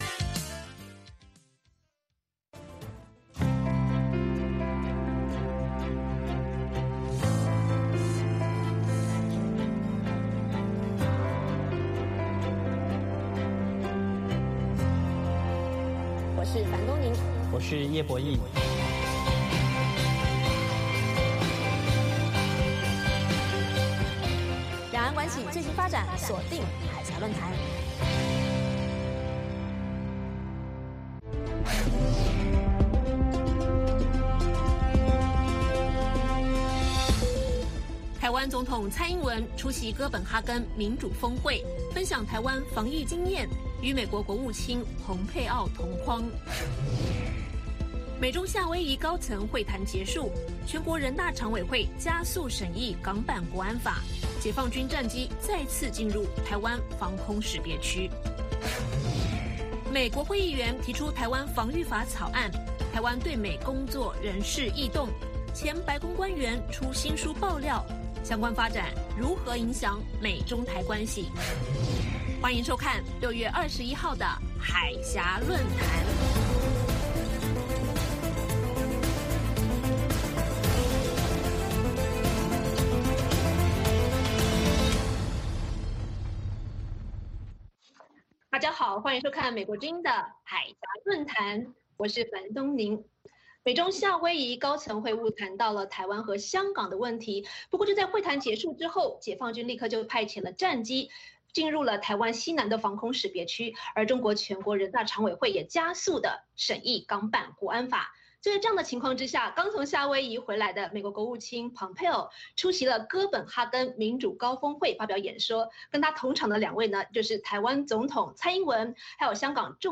北京时间下午5-6点广播节目。广播内容包括新动态英语以及《时事大家谈》(重播)